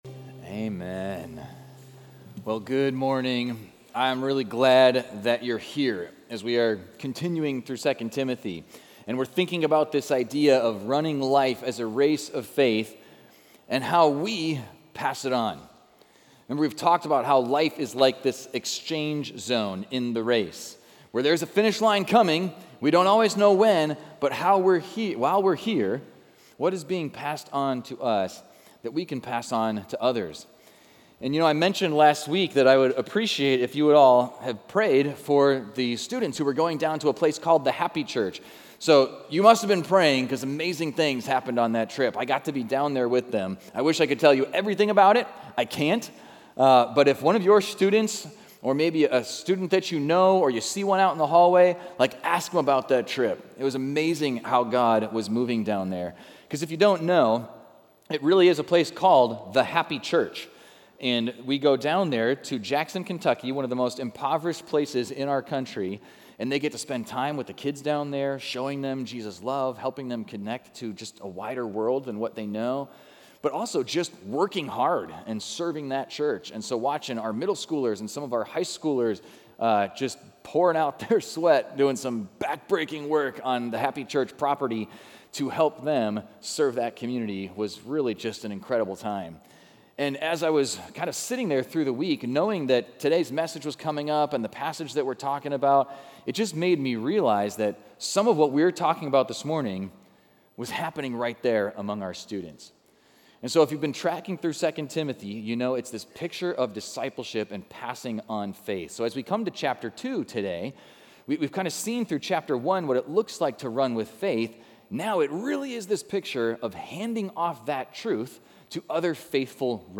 Equipping Service / 2 Timothy: Pass It On / Discipleship 101